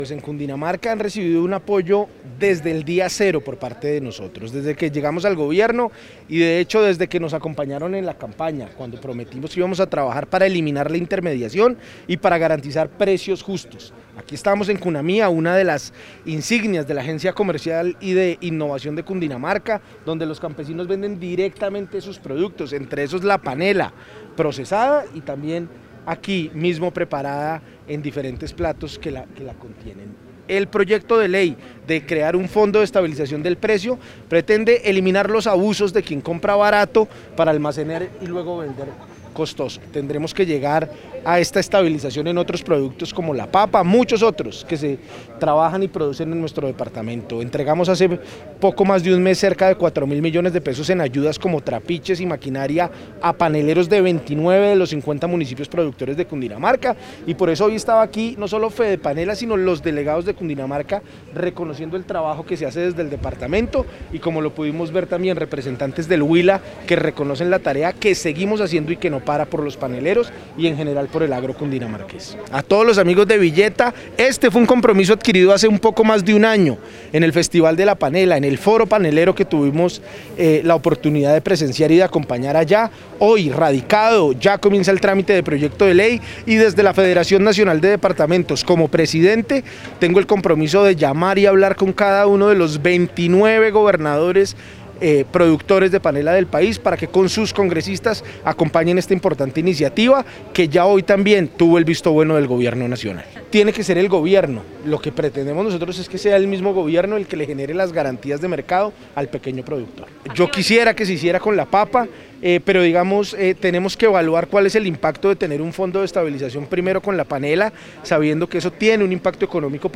Declaración del gobernador de Cundinamarca, Nicolás García.